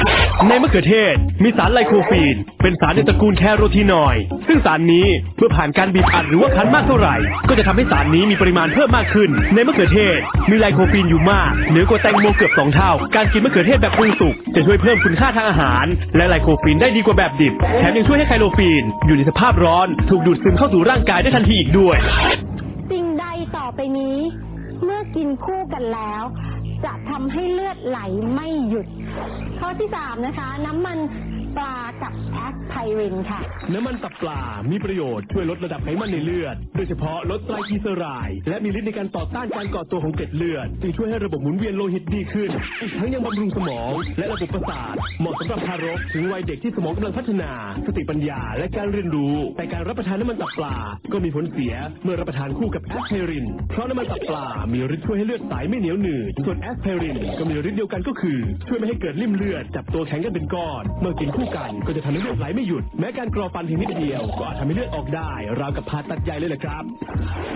วันก่อนเสียงพากย์อ่านสารอาหารในมะเขือเทศว่า "ไล-โค-ฟีน (ฟ.ฟัน)" (ที่ถูกคือไลโคพีน Lycopene)
แล้วเสียงพากย์เดียวกันนี่แหละอ่านไปอ่านมาก็ดันอ่านว่า "ไค-โร-ฟีน" ได้อีก เอากะมันเด้ะ ฮ่าฮ่าฮ่า
ไ่ม่เชื่อลองฟังเสียงอ่านมั่วซั่วของรายการนี้ดิ